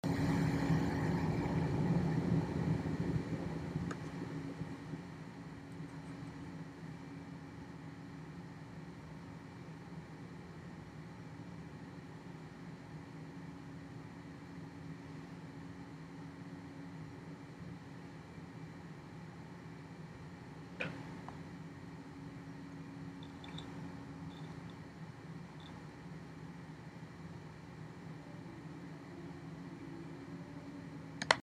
Field Recording for 3/4
Outside of the Calkins dance studio, in the hallway. 6:30PM, 3/1
The only sounds in this are the HVAC system in Calkins, a broom settling against a wall, and the sound of me stopping the recording.